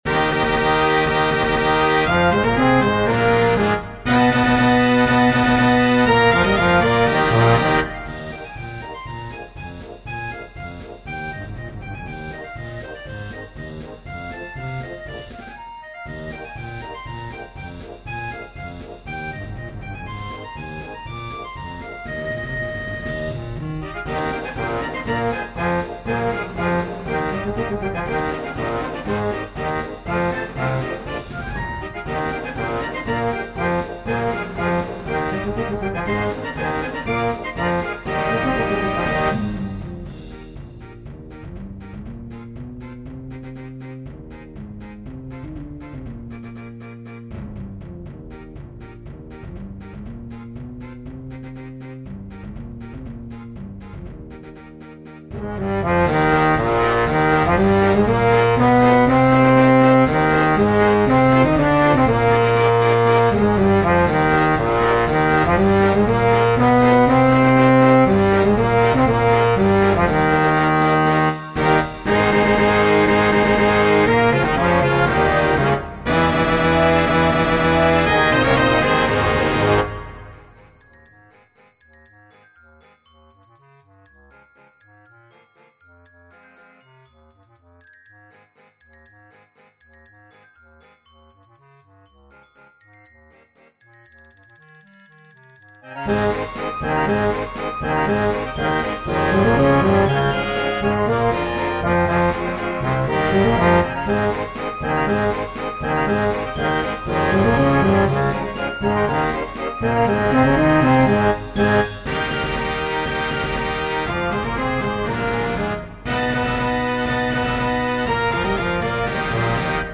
"March Hare", March for Wind Band